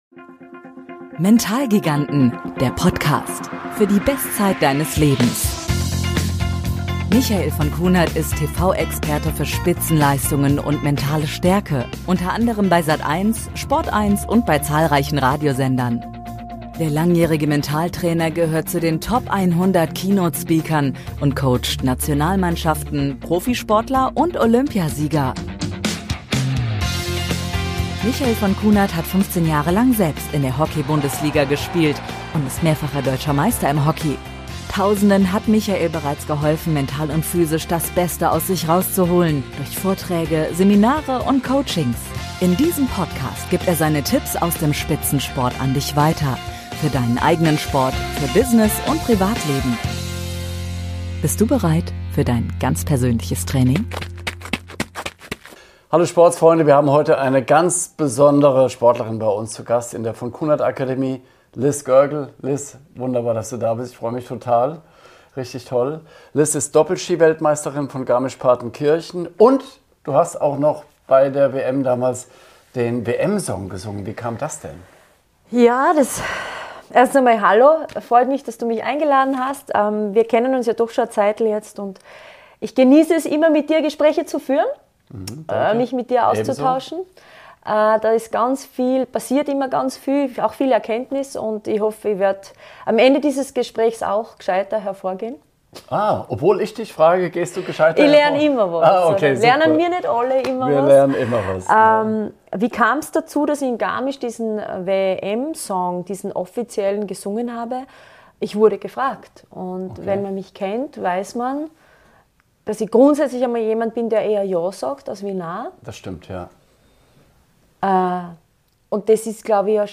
In dieser Episode von Mentalgiganten habe ich die Freude, mit Lizz Görgl zu sprechen – zweifache Weltmeisterin im Ski Alpin, Sängerin und eine echte Inspiration.